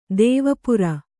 ♪ dēva pura